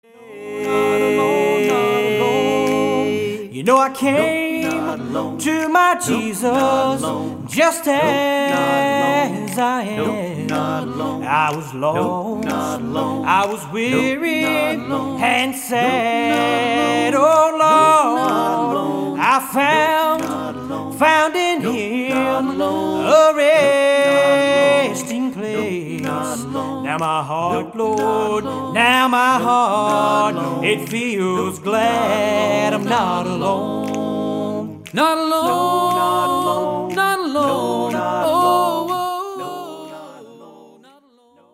Acoustic, Stringed Instrument Band